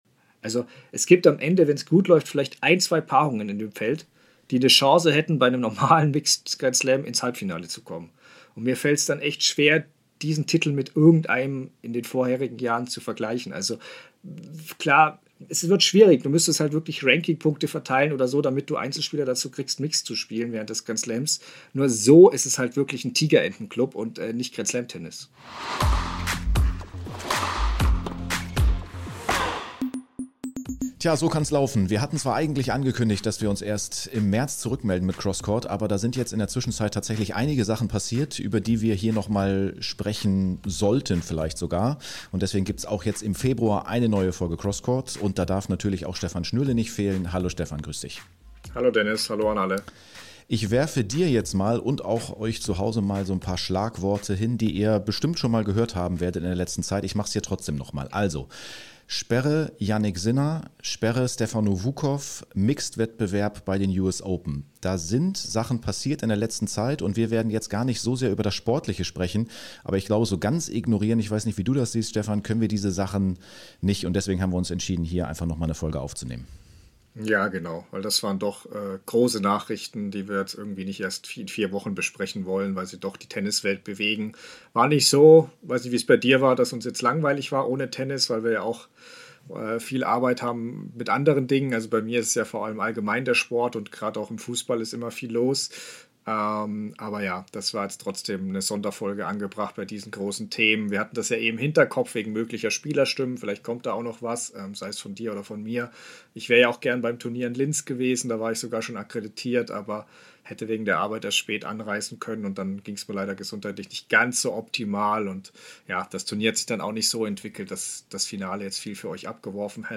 Beschreibung vor 1 Jahr Die Tennis-Welt steht Kopf - daher unterbricht Cross Court seine Pause und meldet sich mit einer Sonderfolge vorzeitig zurück.